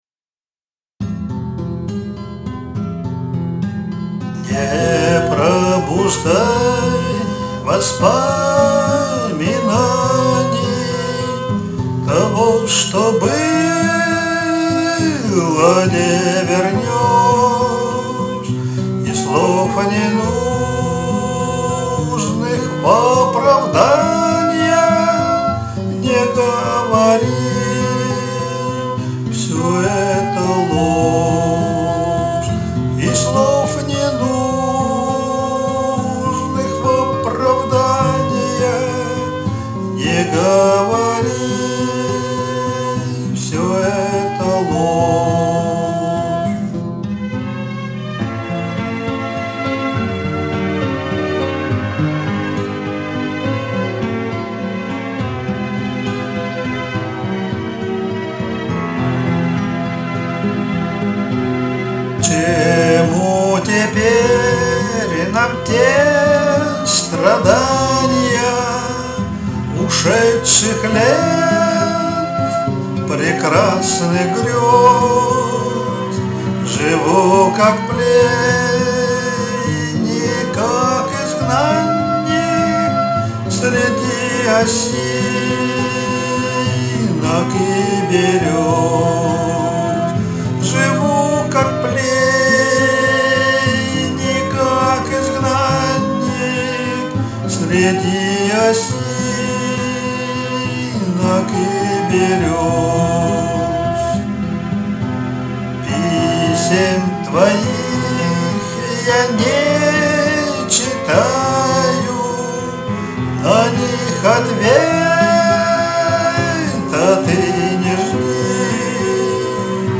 • Автор музыки: Булахов Пётр
• Жанр: Авторская песня
В подражание известного старинного русского романса